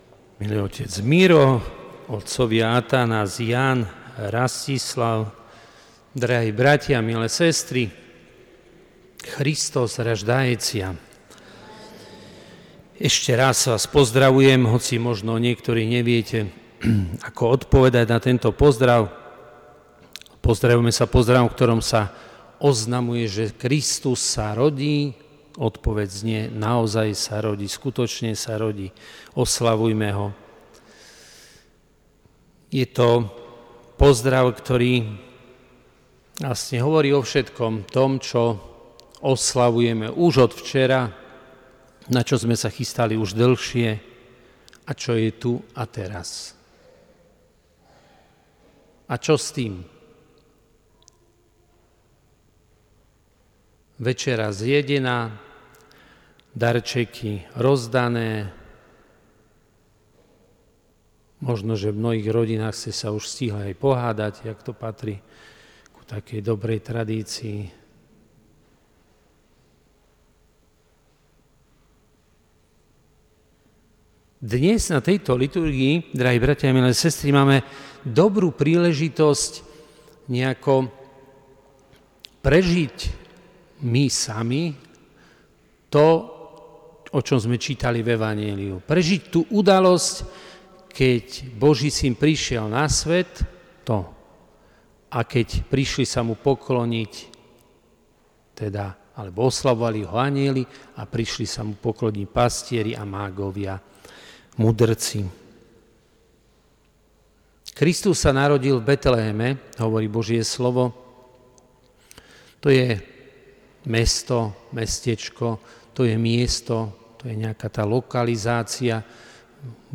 Celú slávnostnú atmosféru podčiarkol spev Zboru sv. Jozefa.